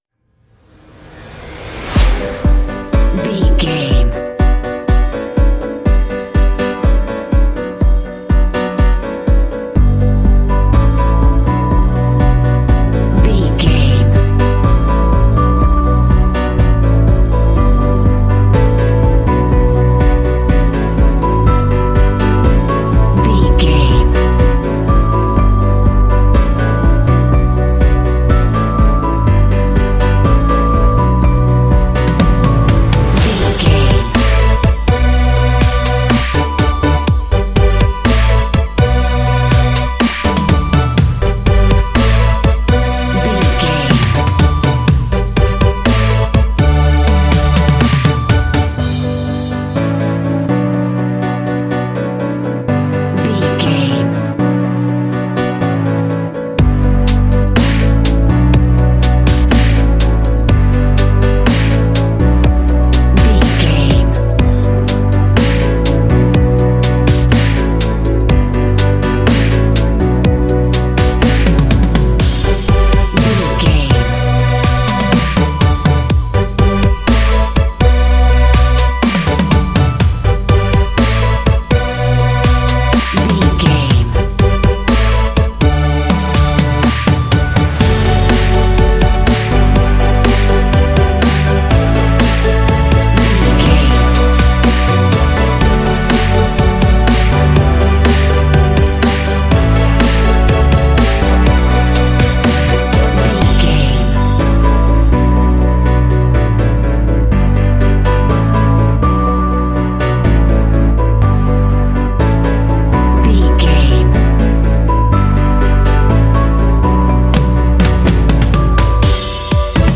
This track makes for a groovy ambient underscore.
Uplifting
Aeolian/Minor
bright
joyful
piano
electric piano
drums
synthesiser
techno